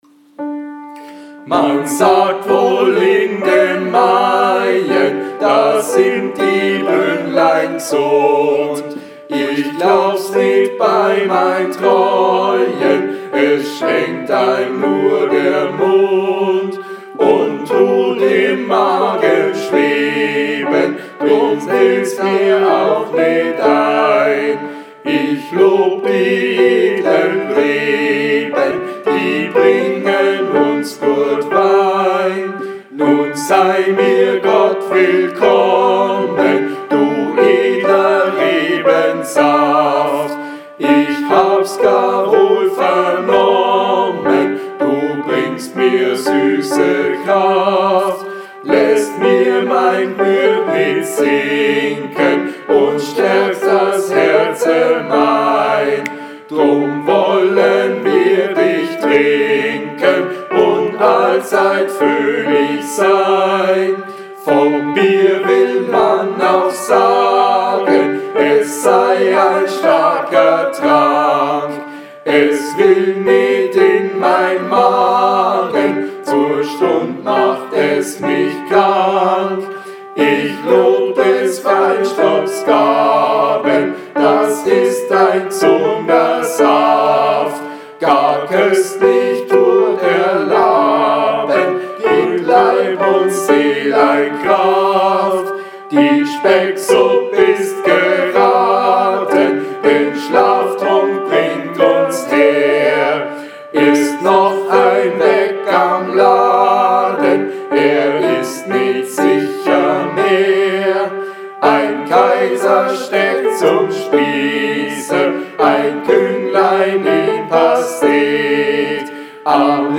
04 Edler Rebensaft ALLE STIMMEN.mp3